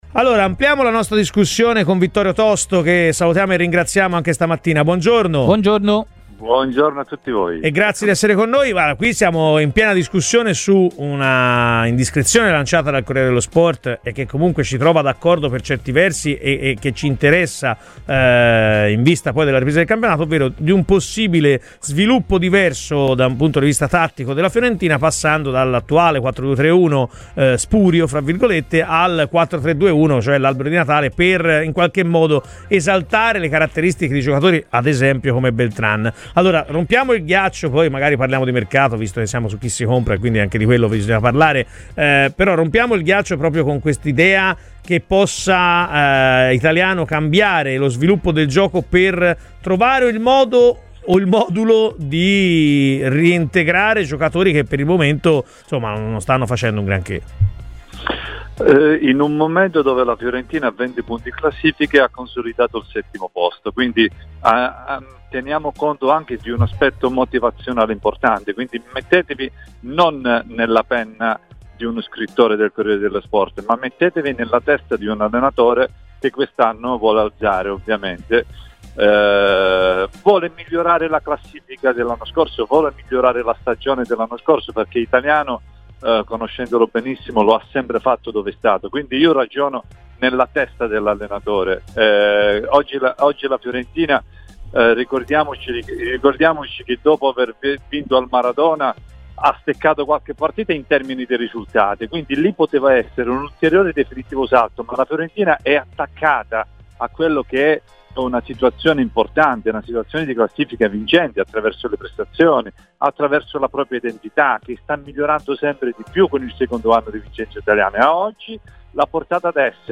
Per l'intervista completa ascolta il nostro podcast!